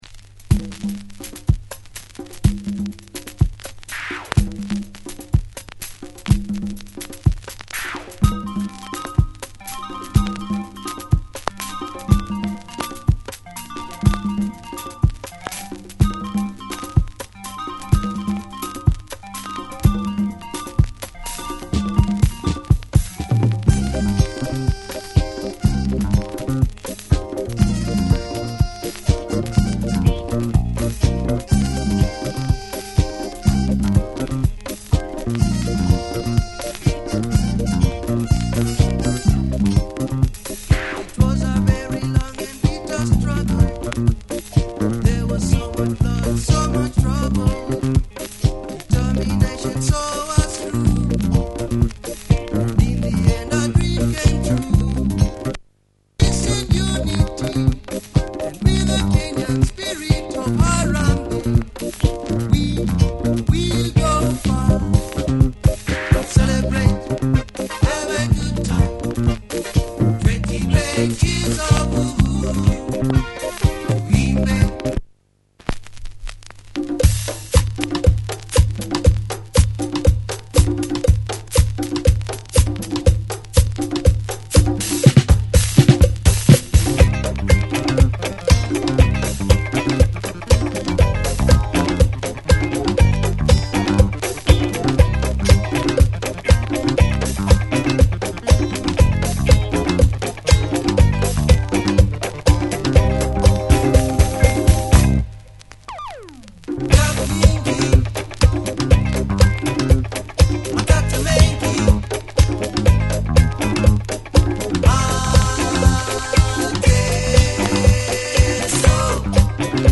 Slightly pop tuned disco effort from this Mombasa group.